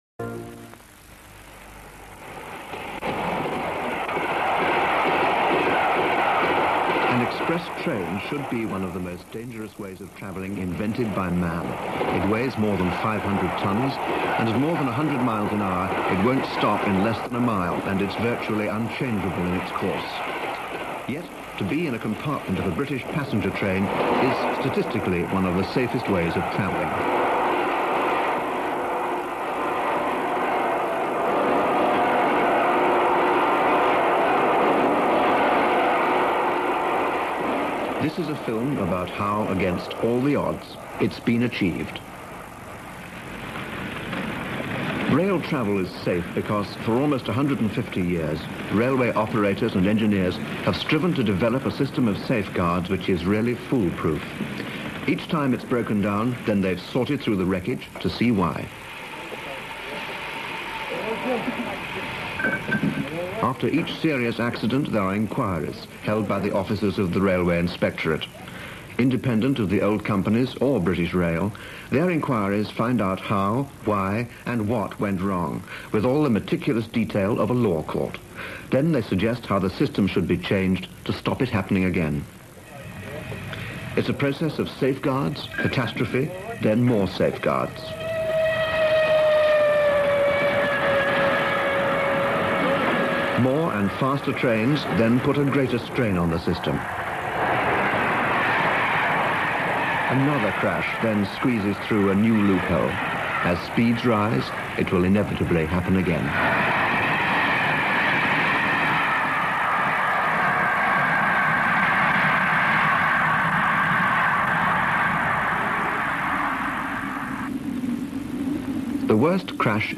BBC HORIZON (1972) - RAIL CRASH full documentary VHS to
From my VHS to AVI collection this episode of popular series Horizon from 1972 - title RAIL CRASH.